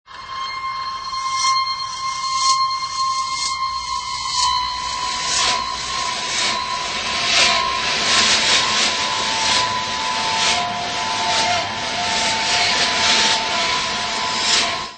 pop indie